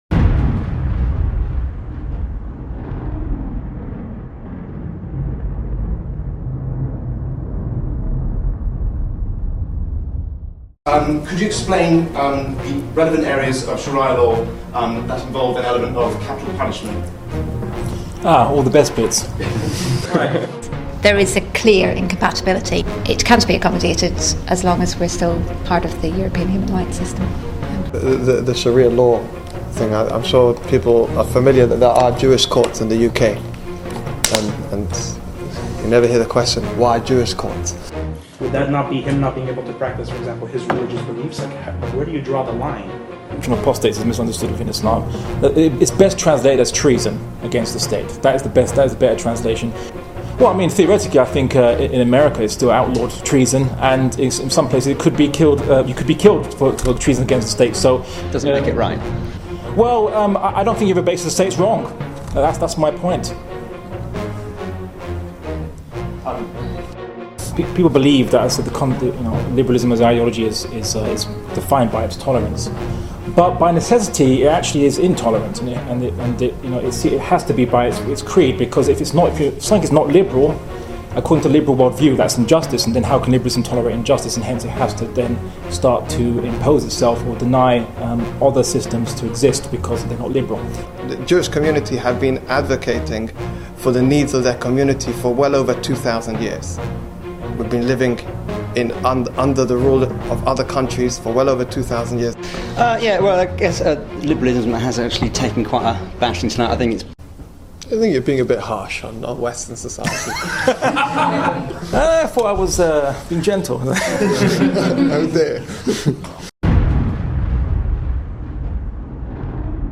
BIG DEBATE： Can Europe tolerate Sharia and other religious laws？.mp3